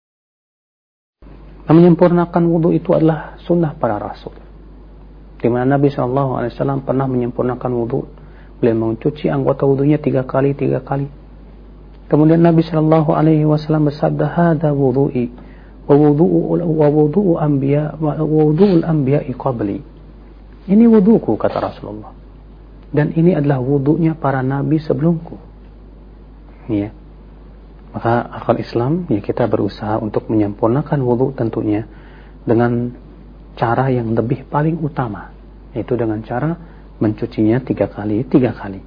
Kajian Audio